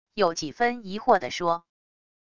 有几分疑惑的说wav音频